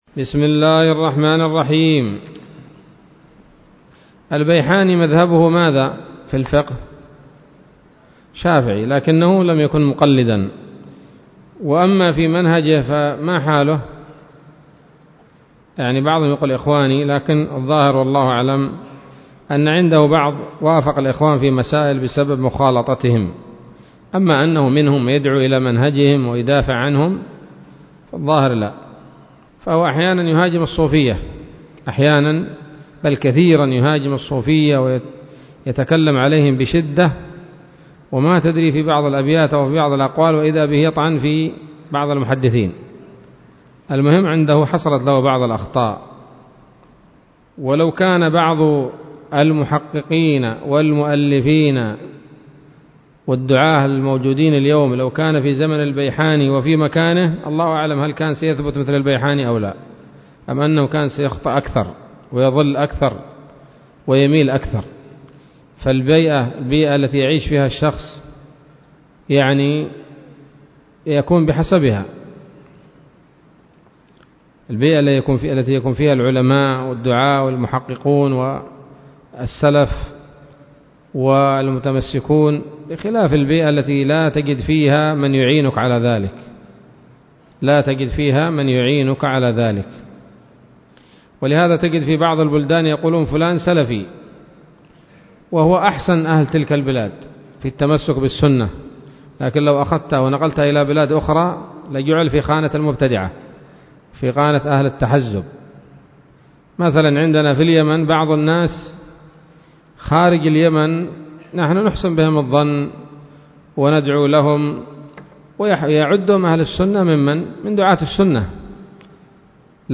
الدروس العلمية تحفة رمضان للعلامة البيحاني [1443هـ] شروح الفقه